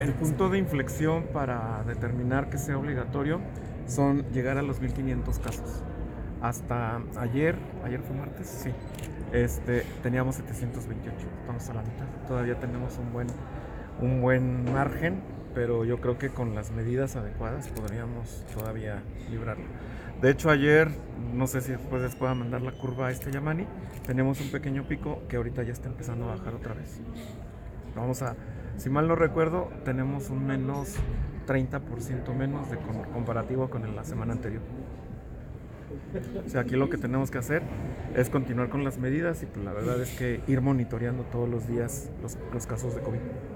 Interrogado sobre el panorama en Chihuahua, el titular de Salud estatal, Felipe Fernando Sandoval Magallanes, manifestó que todavía no se encuentra en estado crítico. En tal sentido, refirió que lo idóneo es que la población continúe con las medidas de prevención mientras los especialistas en la materia monitorean a diario el avance del COVID-19 por si continuara el incremento.